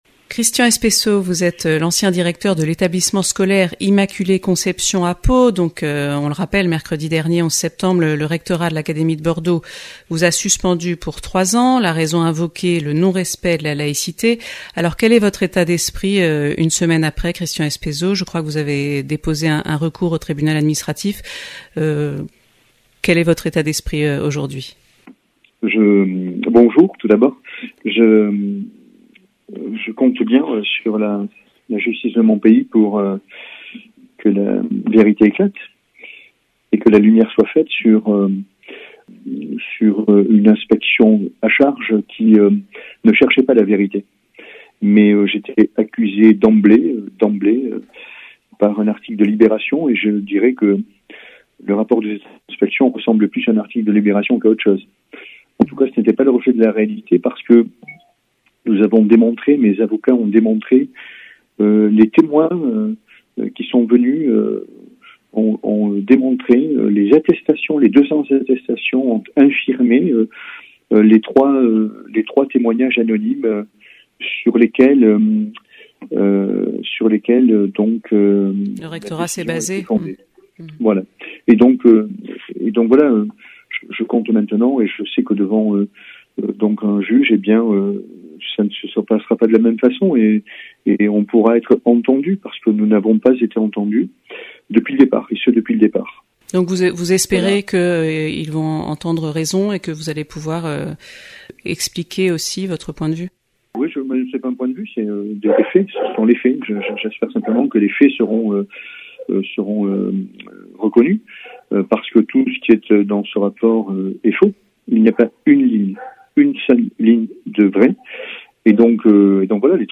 Les visages de l’église en Béarn \ Interview